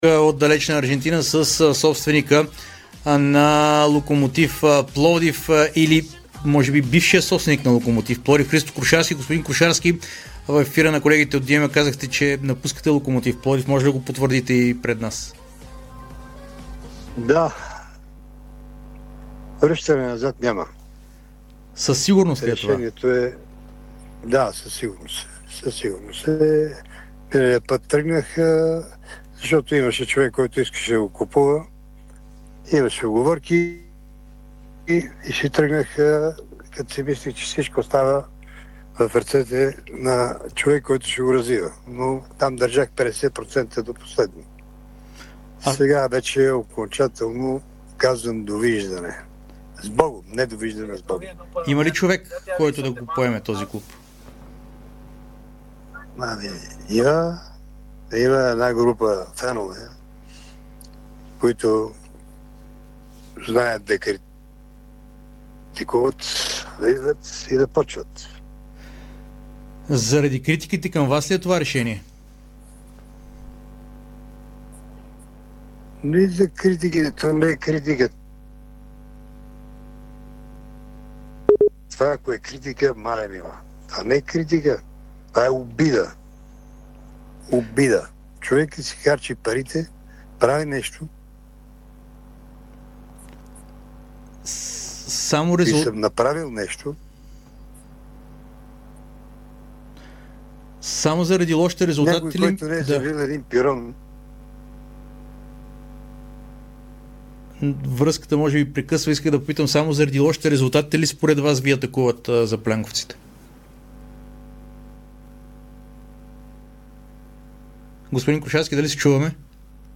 в ефира на Дарик радио